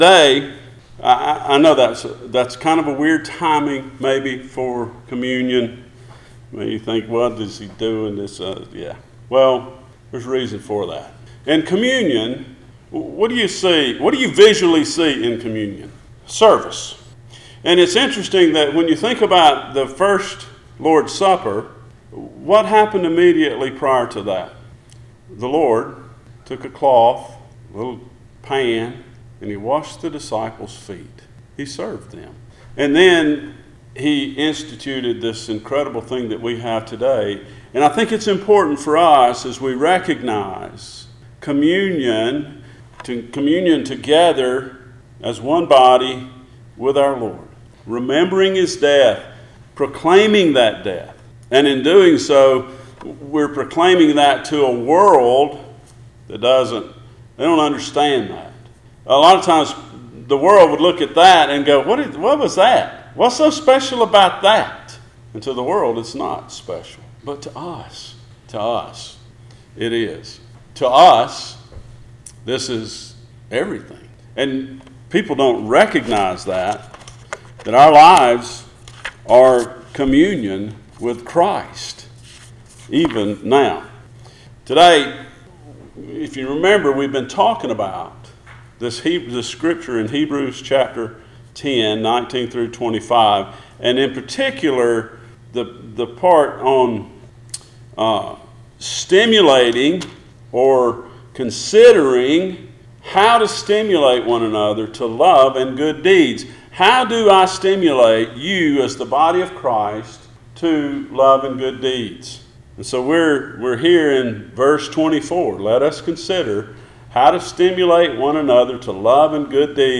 Passage: Hebrews 10:19-25 Service Type: Sunday Morning